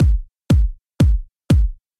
This is a softer sounding drum, with a slightly old, and flat sounding top end, which should help to create a nice distinctive drum – the top end will often be where the character comes into your kick.